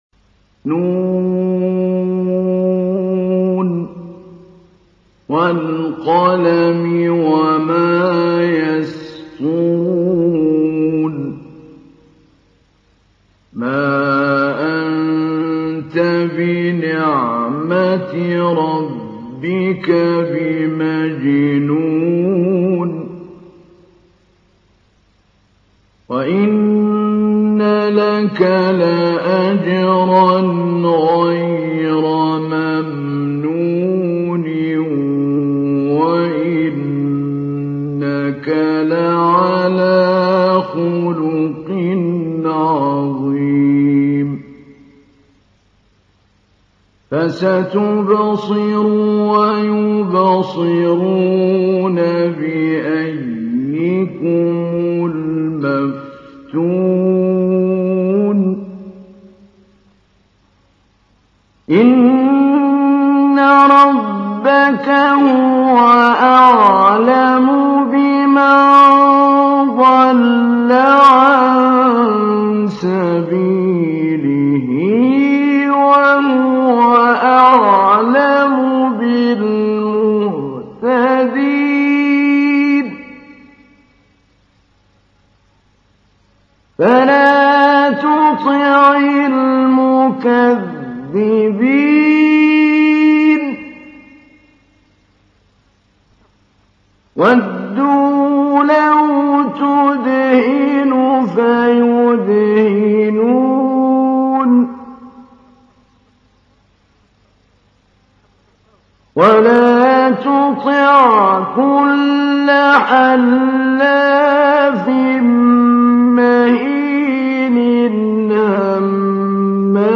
تحميل : 68. سورة القلم / القارئ محمود علي البنا / القرآن الكريم / موقع يا حسين